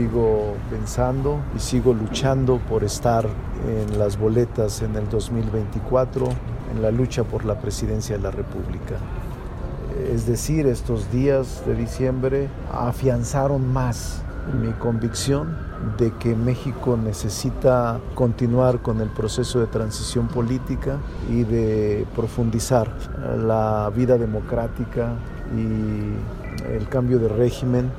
Entrevistado previo a la ceremonia de reconocimiento a la trayectoria del Club Deportivo Guadalajara Femenil organizada por el Senado, el presidente de la Junta de Coordinación Política refirió que diciembre pasado le sirvió para reflexionar.